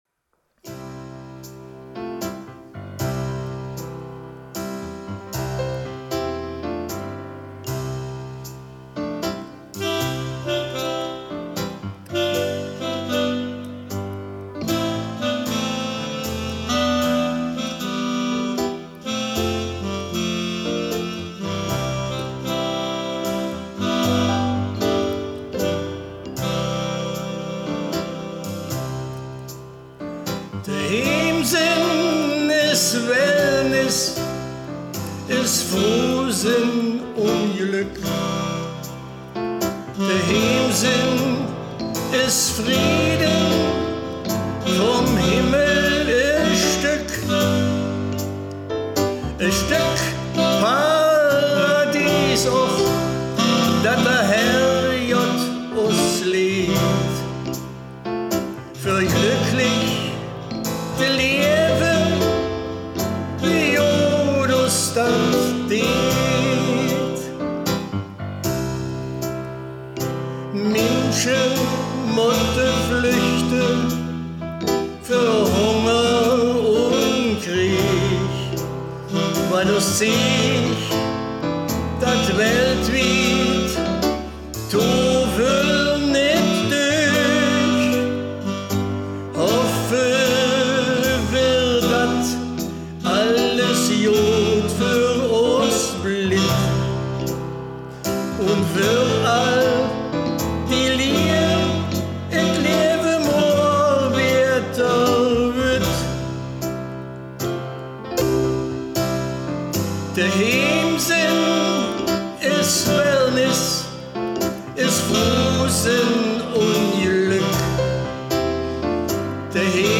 Baaler Riedelland - Erkelenzer Börde
Liedtext